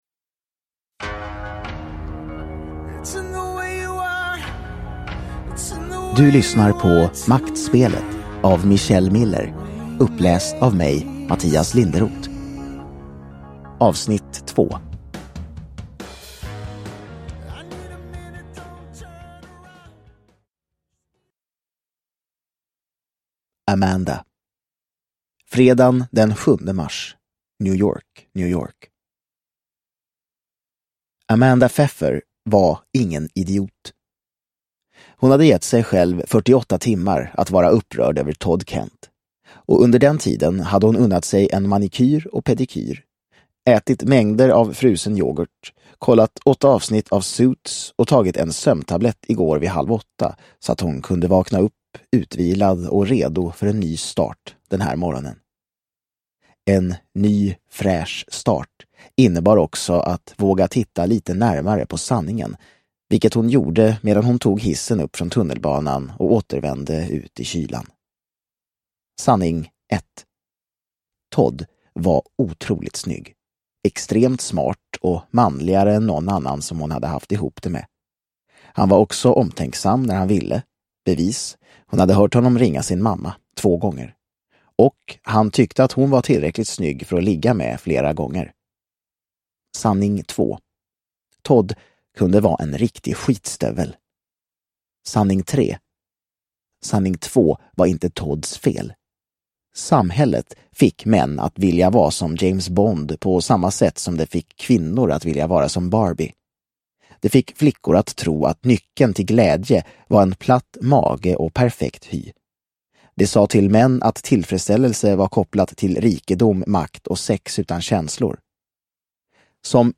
Maktspelet Del 2 – Ljudbok – Laddas ner